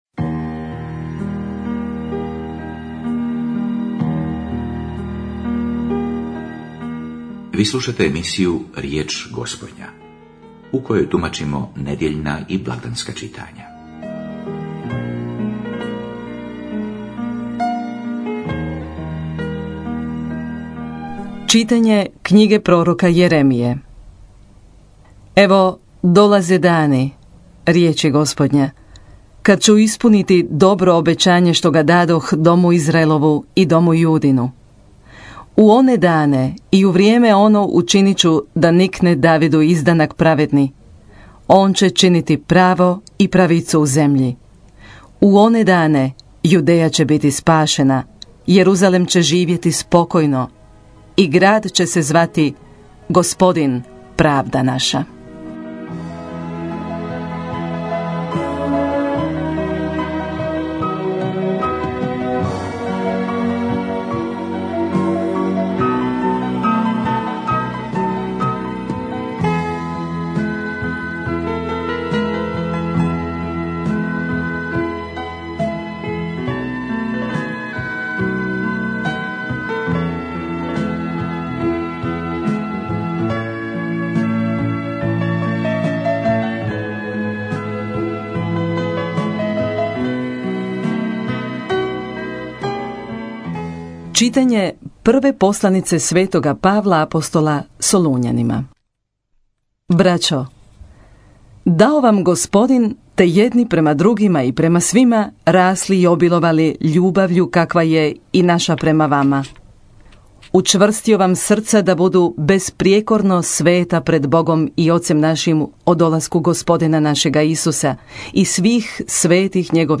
homilija